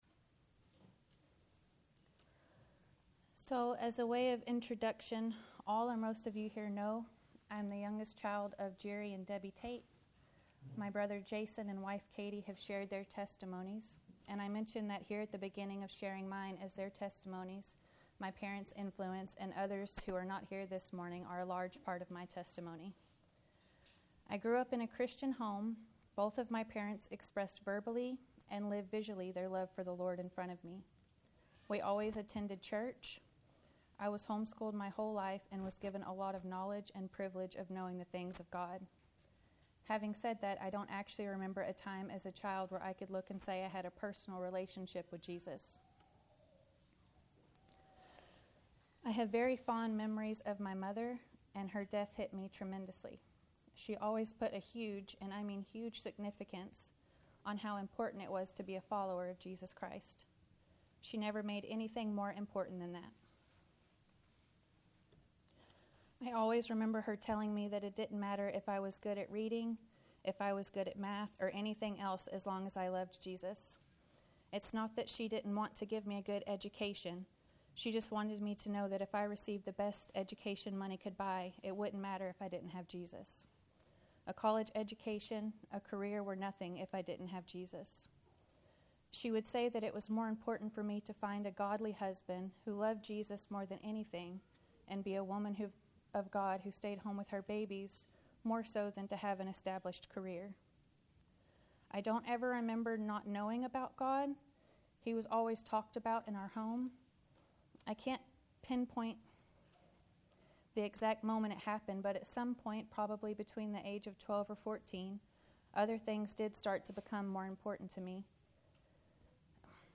Topic Testimony